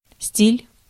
Ääntäminen
US : IPA : [ˈfæʃ.ən]